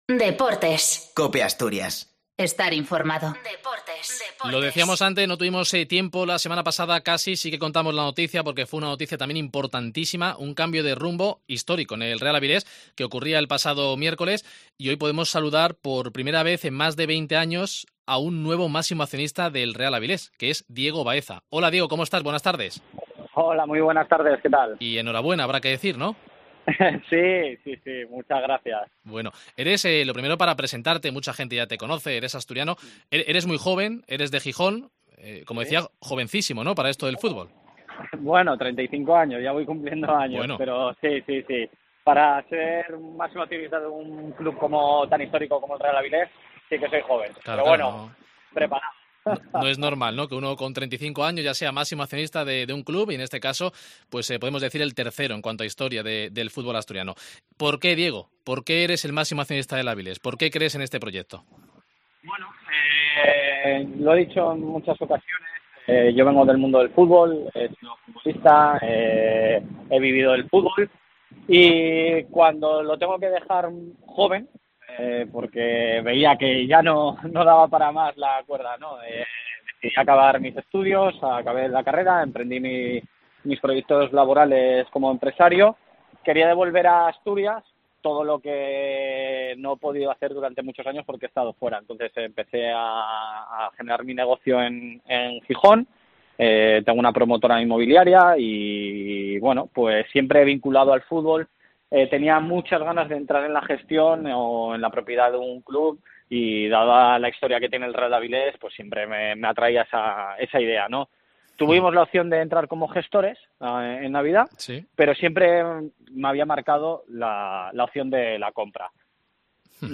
Deportes COPE Asturias ENTREVISTA